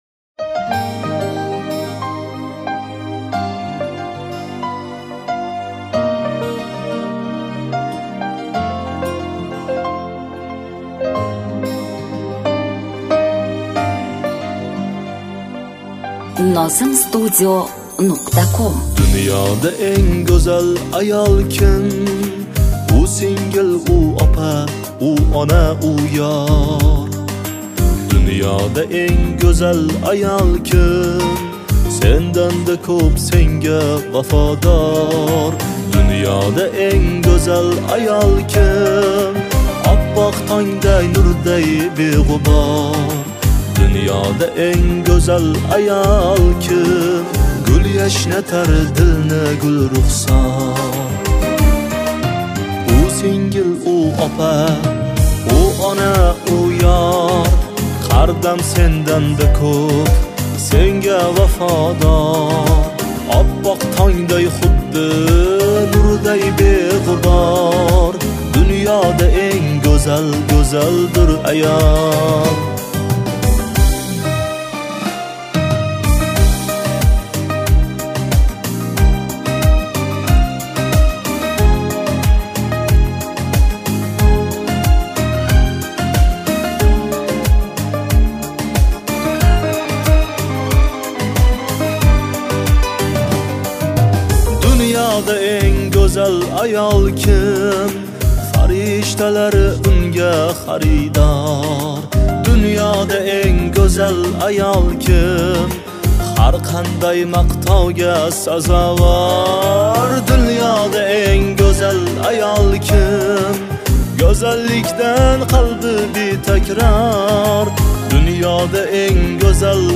UZBEK MUSIC [7816]
minus